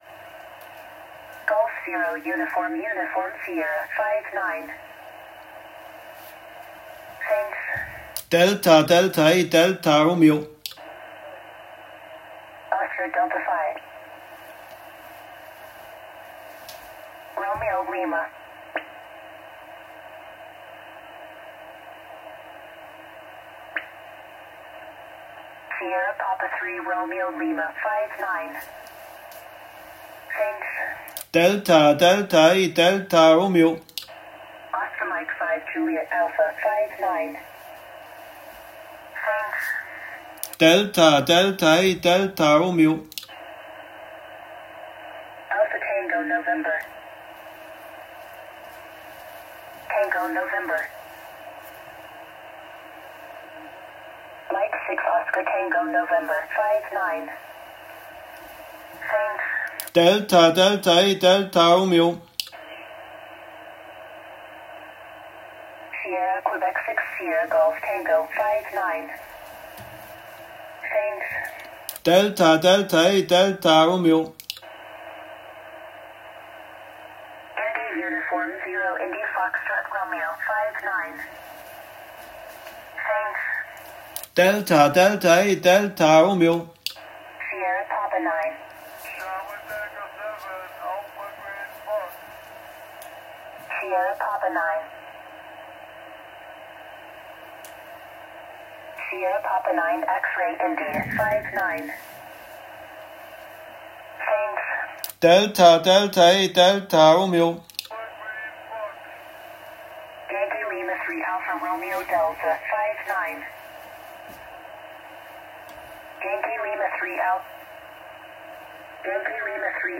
QSO